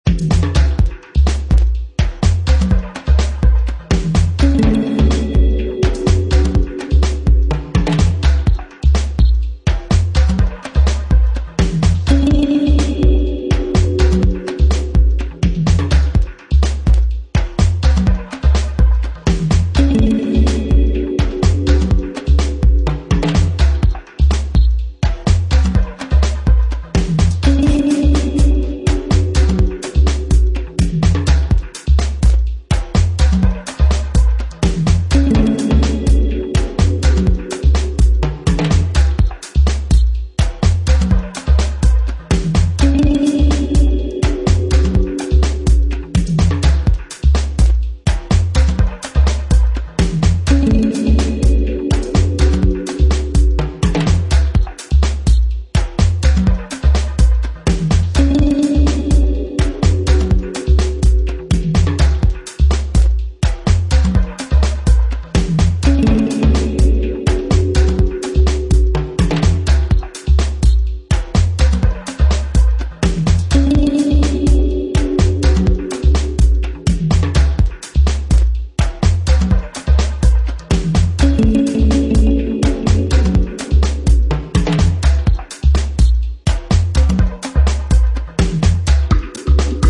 deep and dubby electronic
Techno